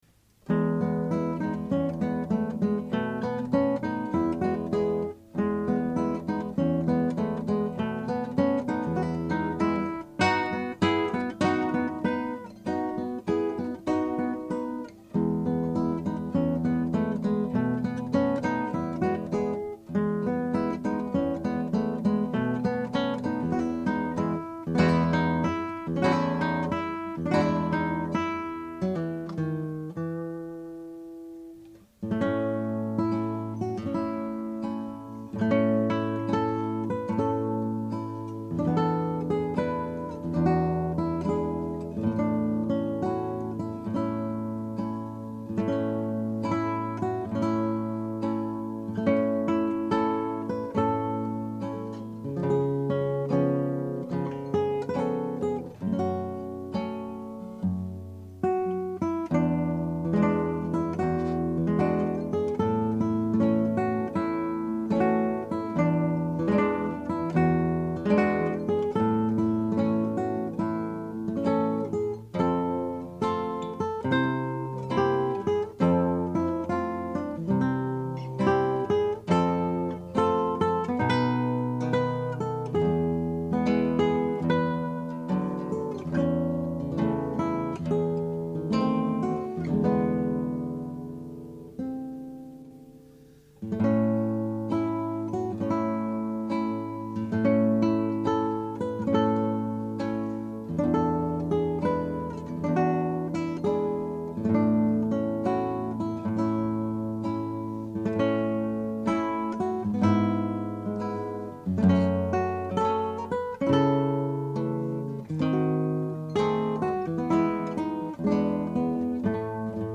Scraps from the Operas arranged for Two Guitars
Scrap 1: Allegretto.
Scrap 2 (0:32): Andante.
Scrap 3 (2:36): Allegro non troppo.